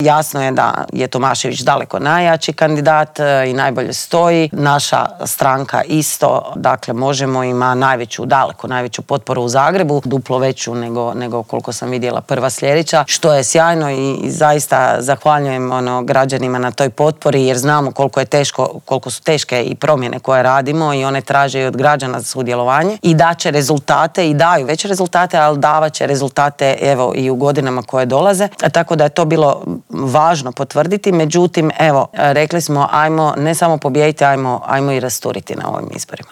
O političkim aktualnostima, nadolazećim lokalnim izborima te o aktualnoj geopolitičkoj situaciji u svijetu razgovarali smo u Intervjuu tjedna Media servisa sa saborskom zastupnicom i koordinatoricom stranke Možemo! Sandrom Benčić.